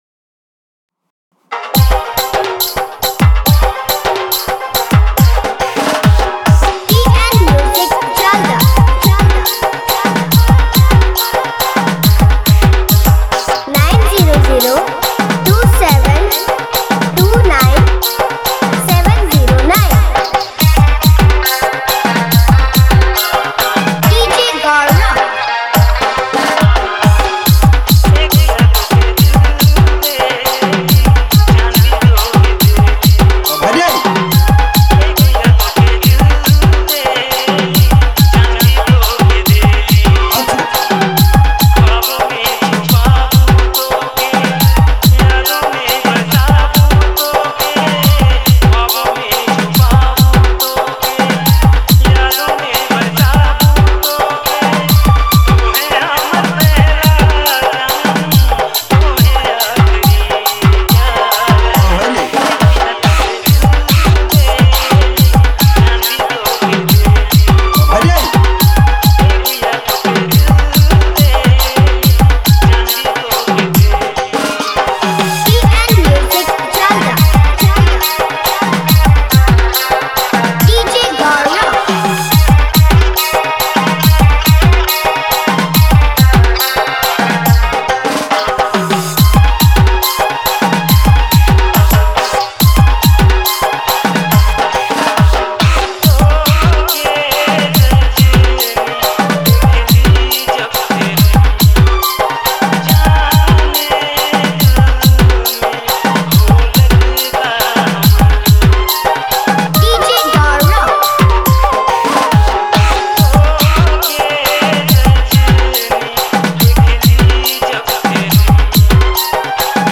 Nagpuri remix